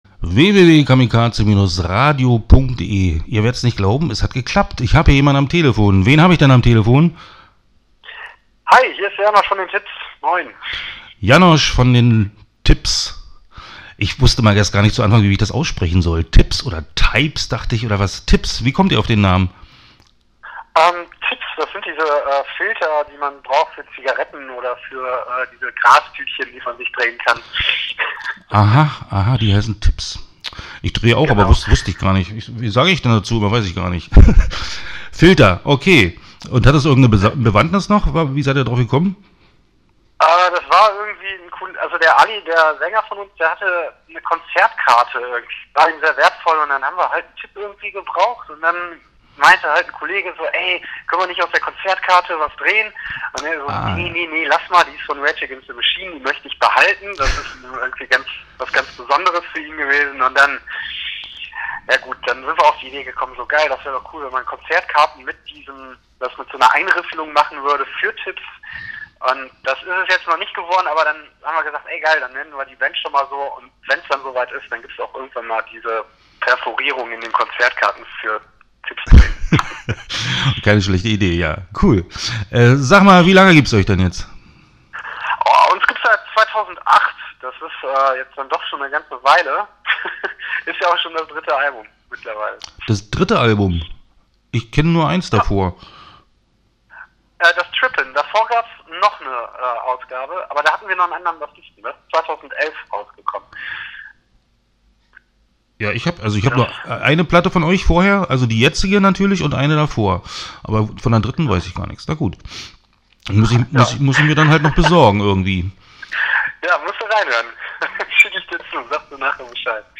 Start » Interviews » The Tips